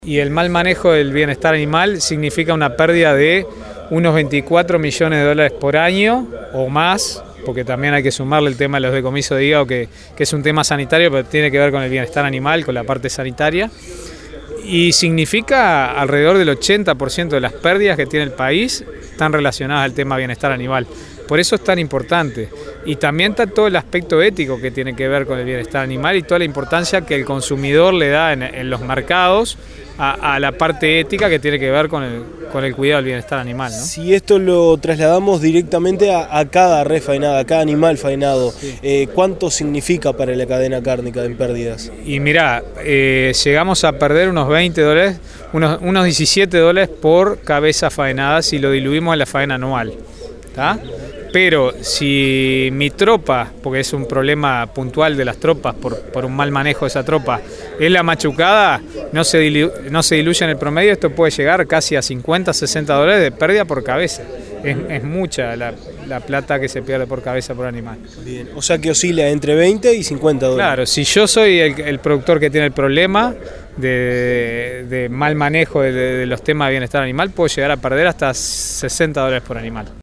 Con el fin de difundir esta problemática, el instituto está realizando una serie de charlas informativas en Expo Melilla.